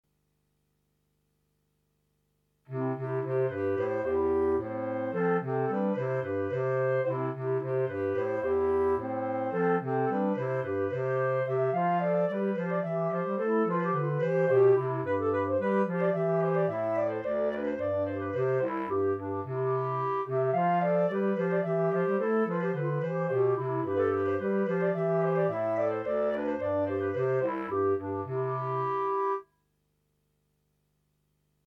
Right click to download Bourree minus Clarinet 3